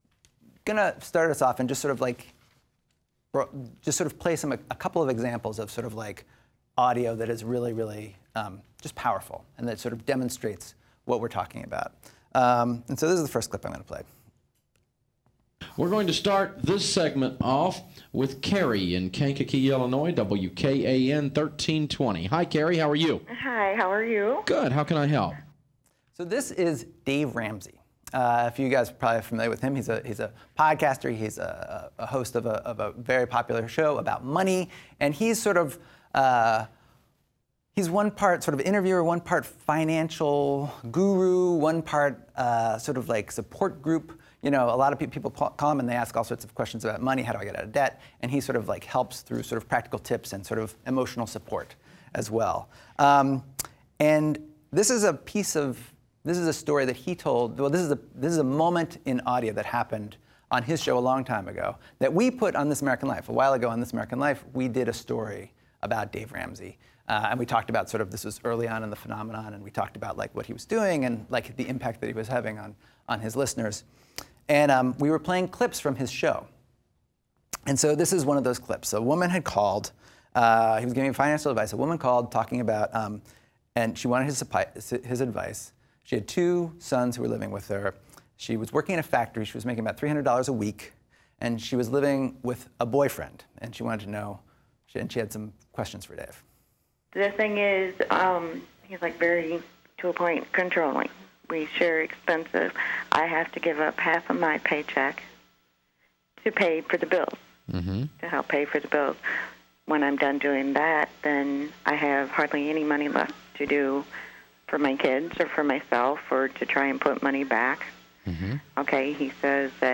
Below is a clip from the workshop, please listen to it. Blumberg played an authentic and emotionally powerful piece that was part of a This American Life story about a radio host named Dave Ramsey.
It’s raw and unfiltered and the listener immediately feels empathy for Ramsey’s guest, a woman in a really difficult position.
In the example, Ramsey knows the woman is lying about abuse because he can hear the smallness of her voice — he can hear the lie.